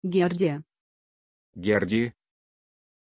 Pronunciación de Geordie
pronunciation-geordie-ru.mp3